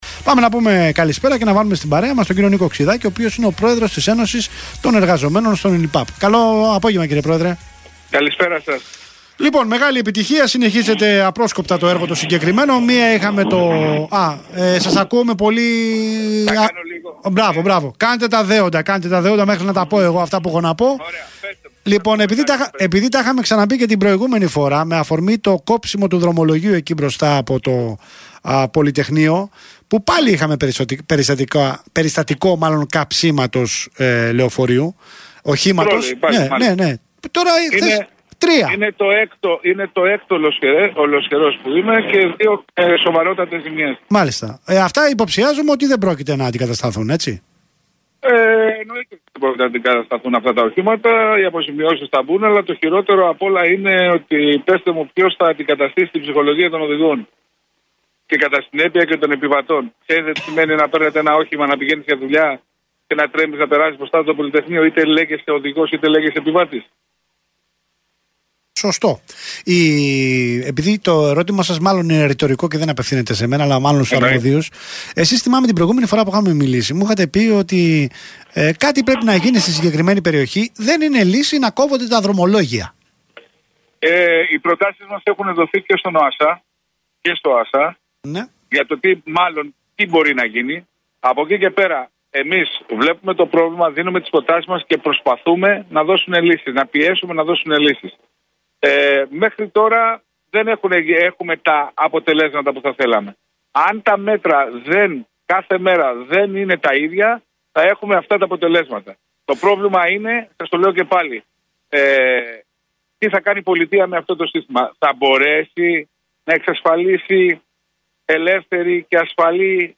Ραδιοφωνική συνέντευξη